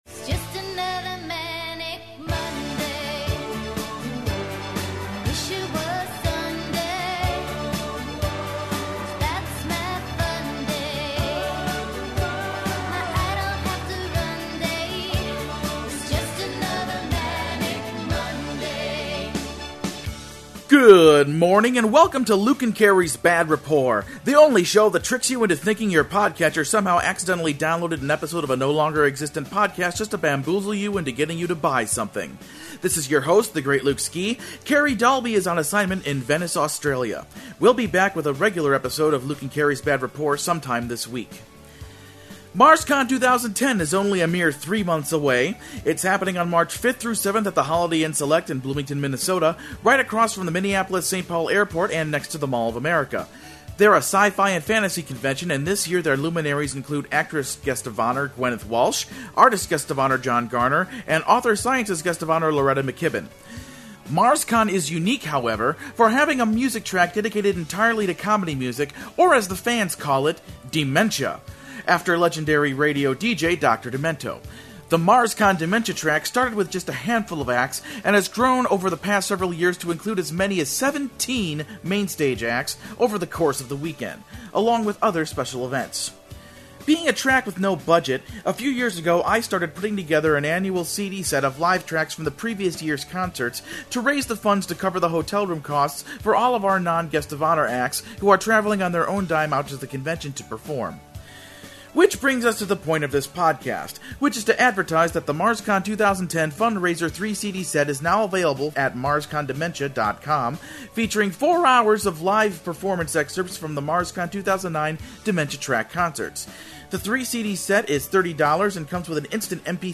This is not a regular episode of Bad Rapport, this is a commercial for the MarsCon 2010 Dementia Track Fund Raiser 3-CD & MP3 sets, which you can purchase at MarsCon Dementia dot com.